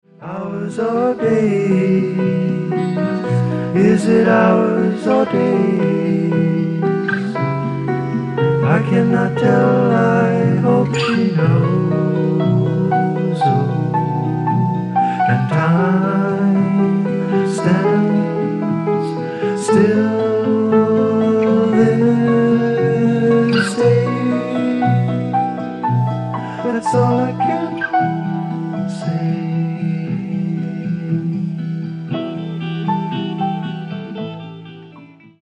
Progressive Rock
デビュー作とは思えないほど、ファンキーかつハードなグルーヴを生み出している。